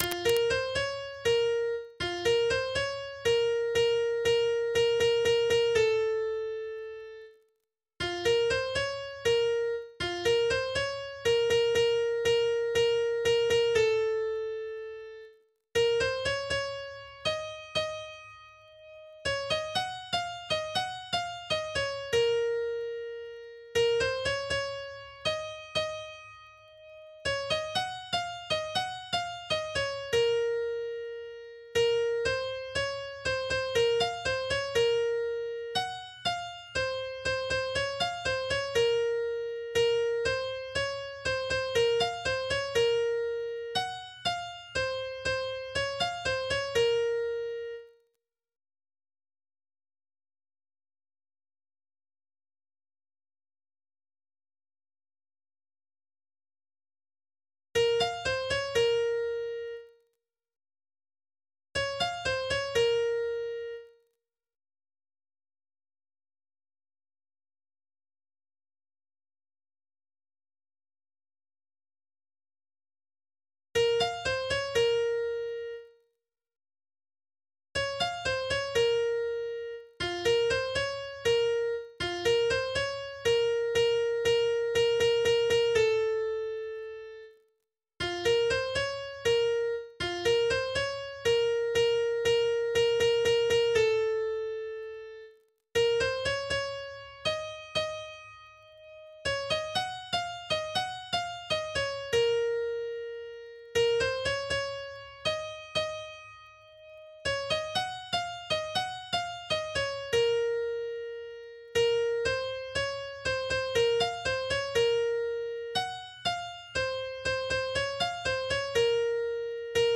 MIDI 2.28 KB MP3